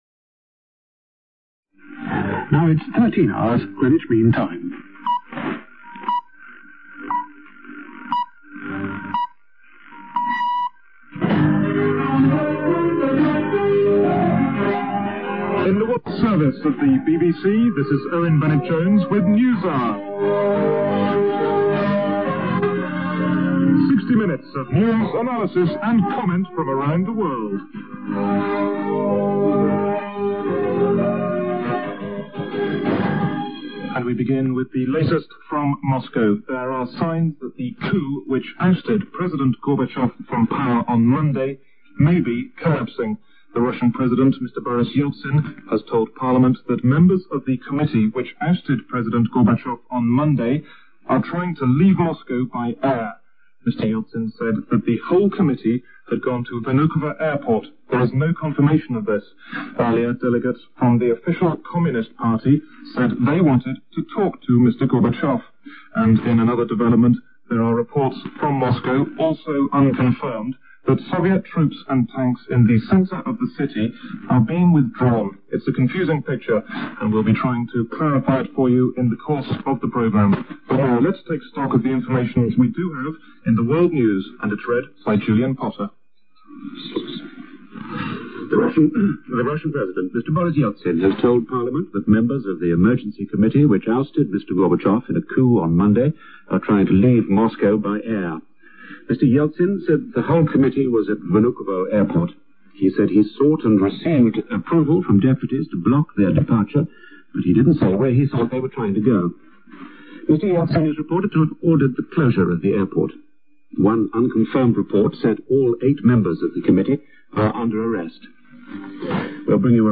A shortwave transmission from Radio Peking. Propaganda recording about the challenge to world peace posed by "the U.S. Imperialists”.